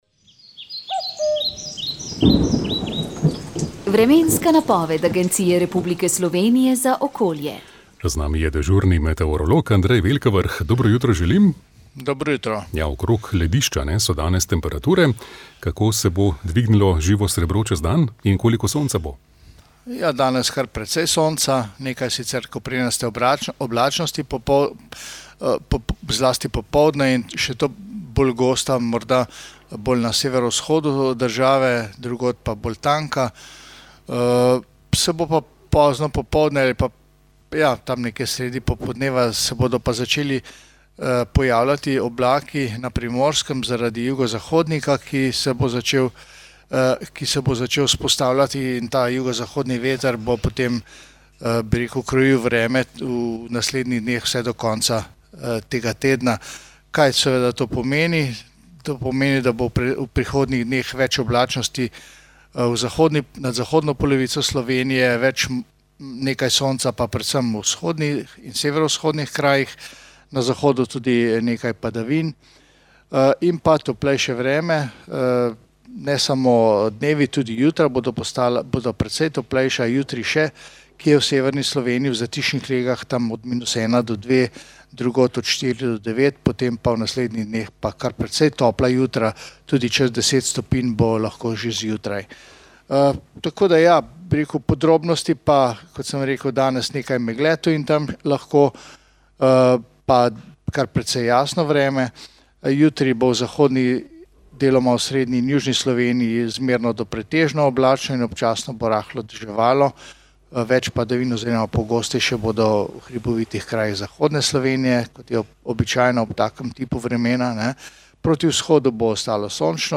Sv. maša iz cerkve Marijinega oznanjenja na Tromostovju v Ljubljani 27. 10.